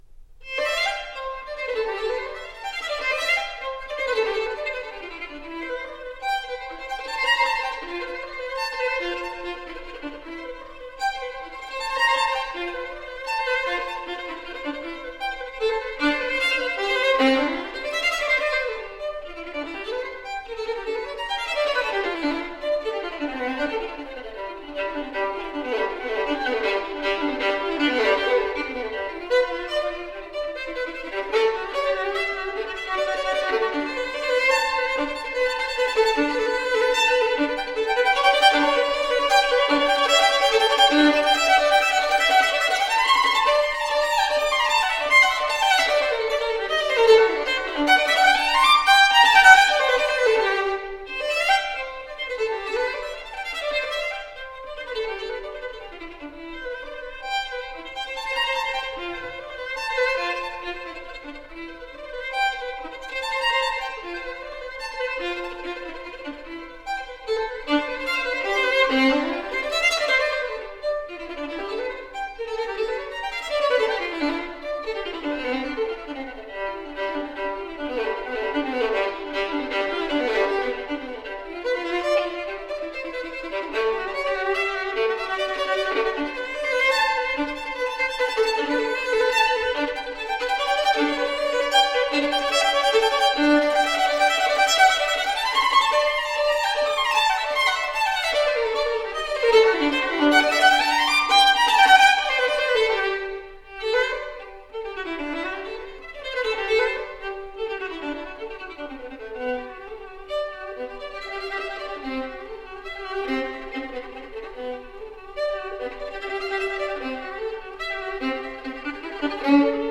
so the resulting music sounds incredibly fresh and alive.
Classical, Baroque, Instrumental